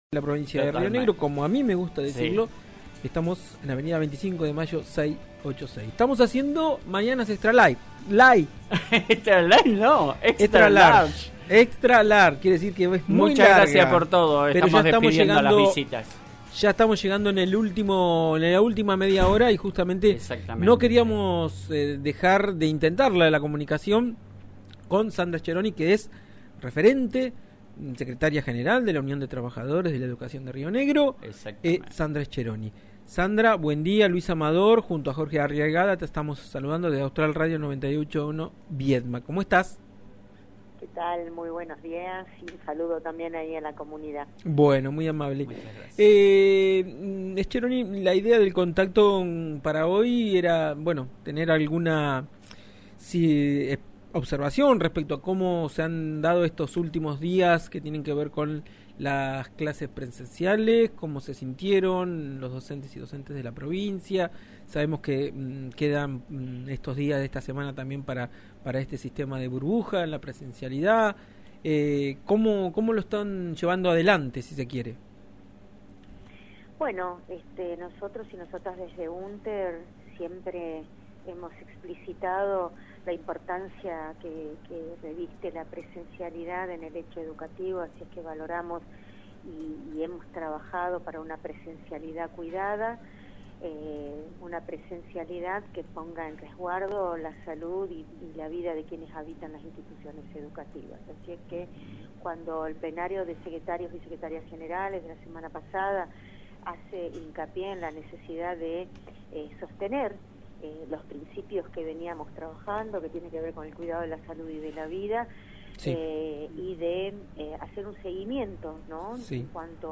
Secretaria General entrevista radial el 28/06/21